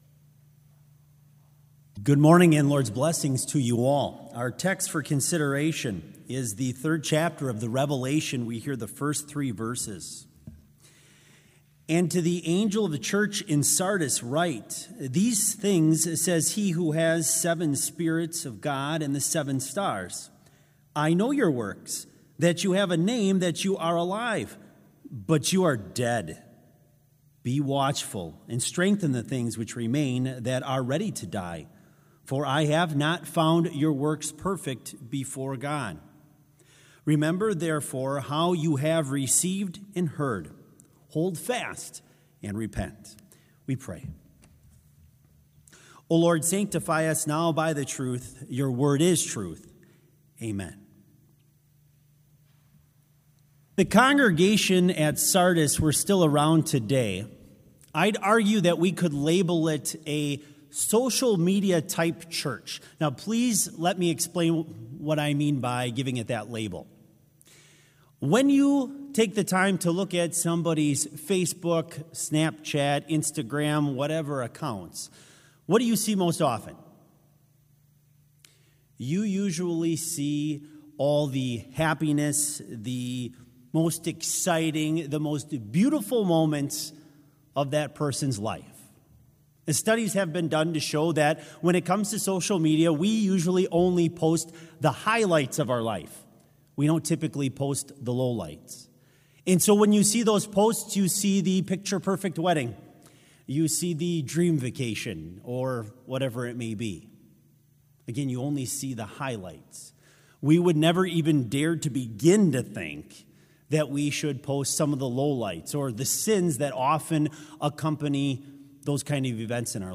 Complete service audio for Chapel - October 11, 2019